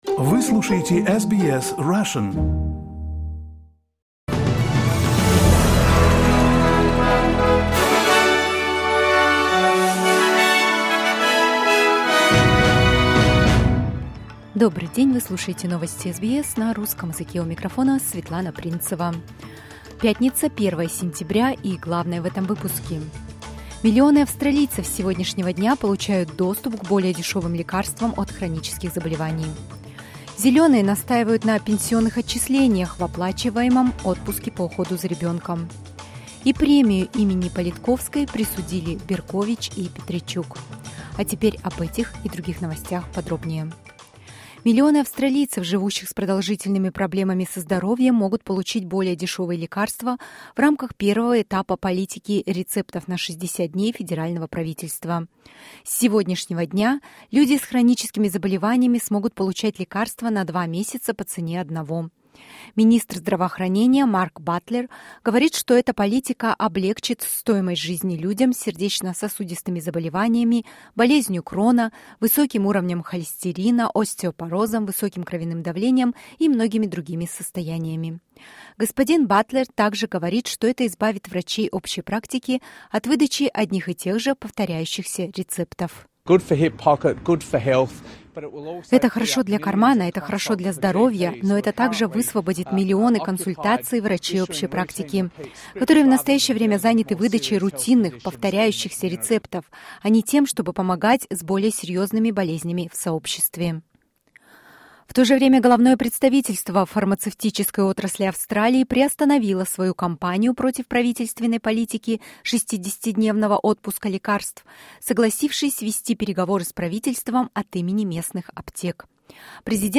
SBS news in Russian — 01.09.2023